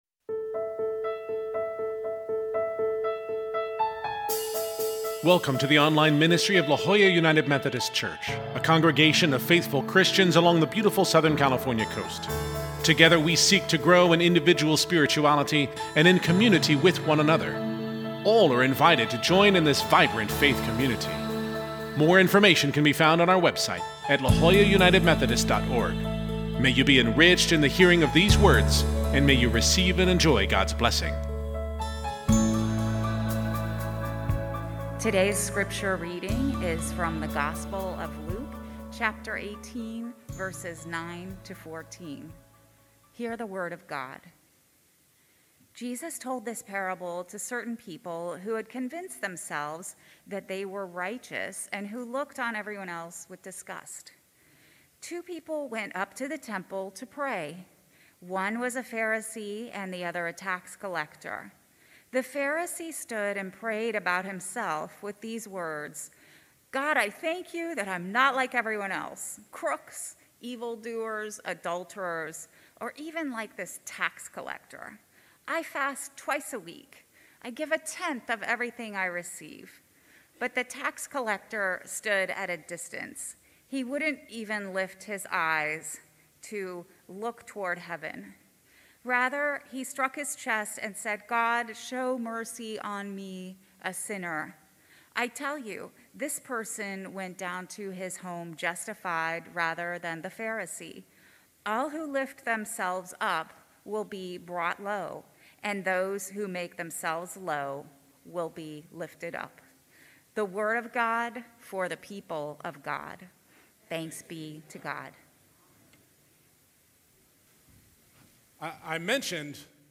This Sunday we continue our sermon series on the parables of Jesus with the parable of the Pharisee and the tax collector.